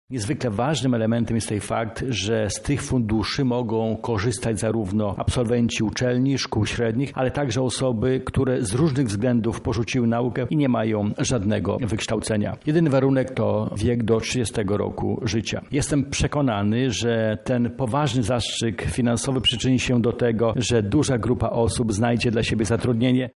O tym, kto może skorzystać z programu, mówi wicemarszałek Krzysztof Grabczuk.